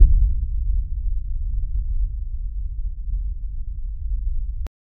Cinematic battlefield ambient: light armor clinks, distant war drums, soft desert wind, faint footsteps, subtle metal hums. No music, just tense and atmospheric sound. 0:05 Created Apr 15, 2025 3:30 PM
cinematic-battlefield-amb-ebnsp2sr.wav